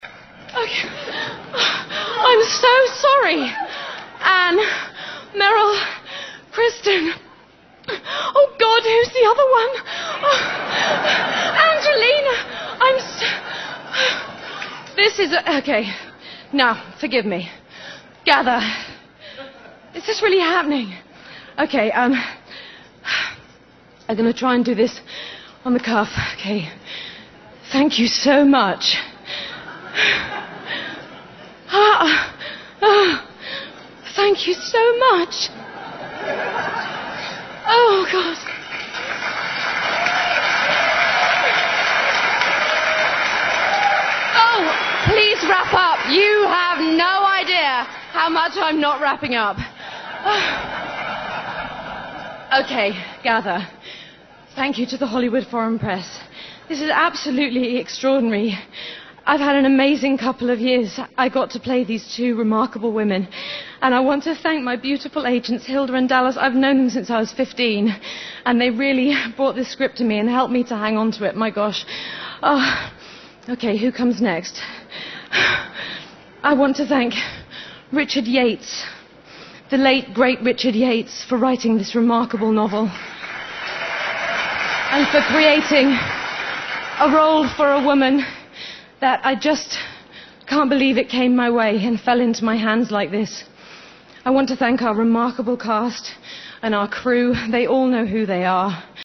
在线英语听力室偶像励志英语演讲 第89期:凯特·温斯莱特获得第66届金球奖剧情类最佳女主角(1)的听力文件下载,《偶像励志演讲》收录了娱乐圈明星们的励志演讲。